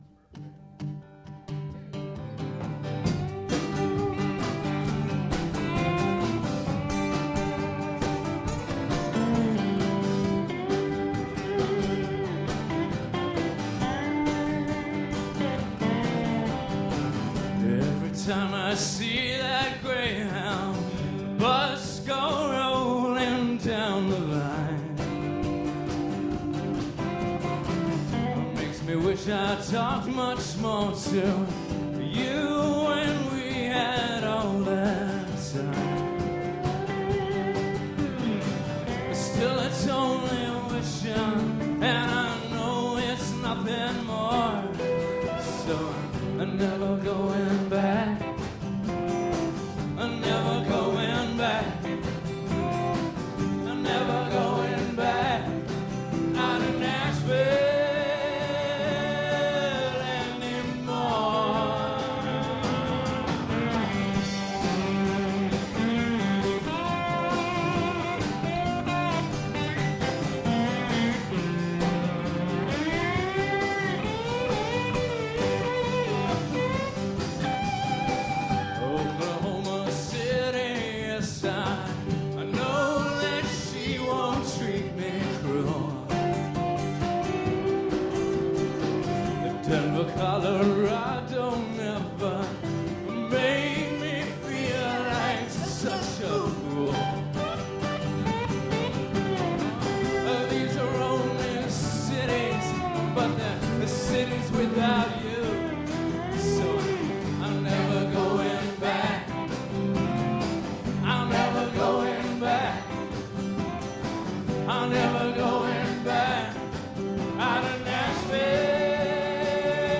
to do a special Tribute Concert
They keep alive his vision of The World’s Loudest Folk Band!